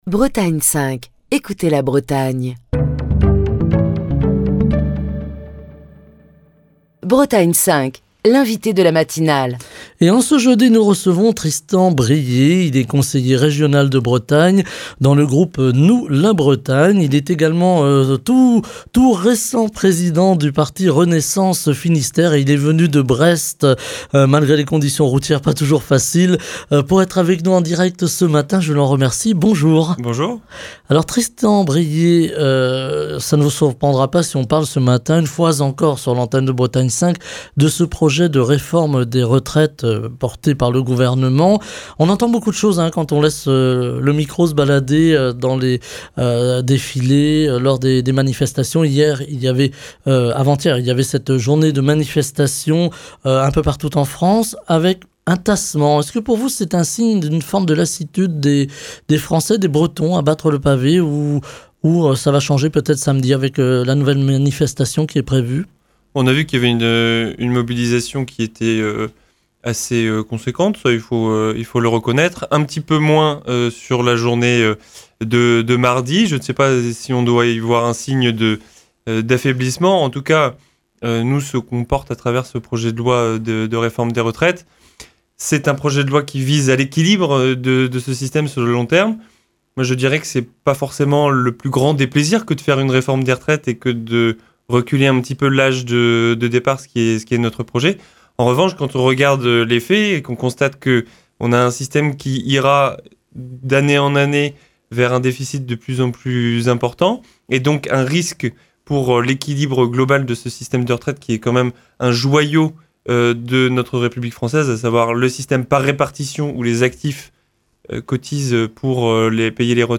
Nous en parlons ce matin avec notre invité, Tristan Bréhier, Conseiller régional de Bretagne group